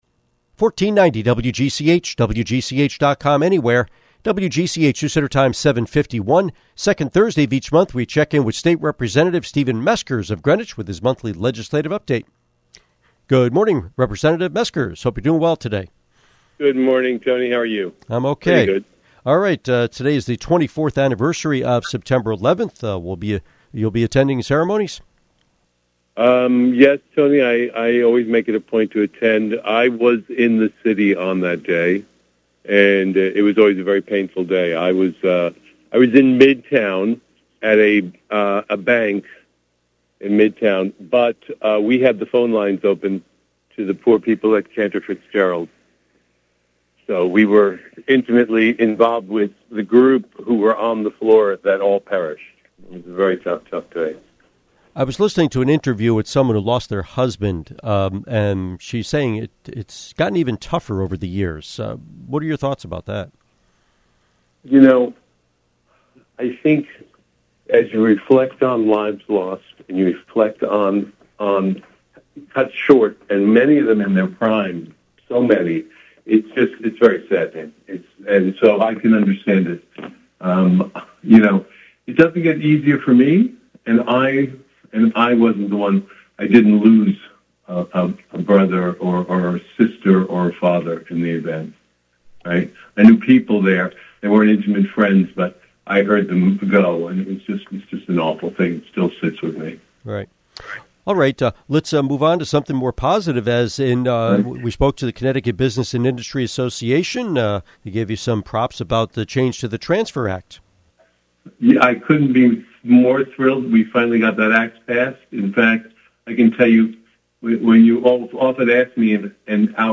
Interview with State Representative Stephen Meskers